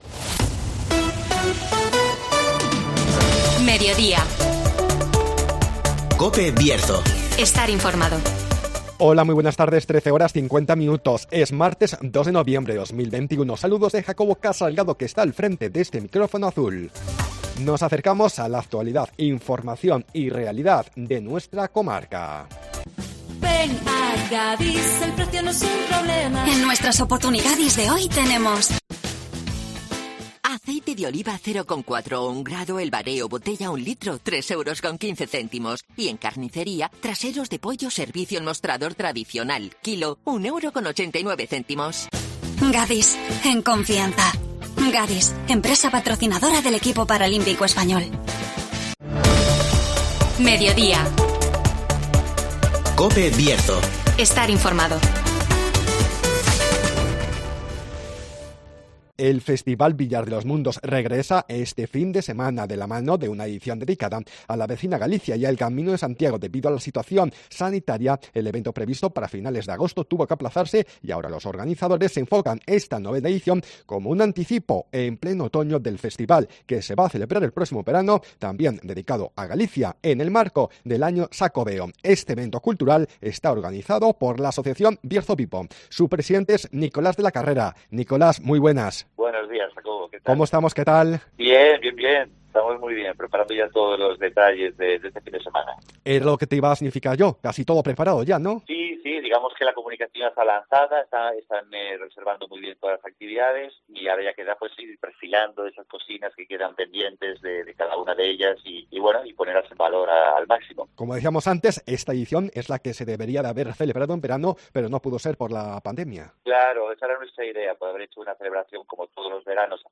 El IX festival Villar de los Mundos rinde homenaje a la vecina Galicia y al Camino de Santiago (Entrevista